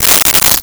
Coins In Hand 03
Coins in Hand 03.wav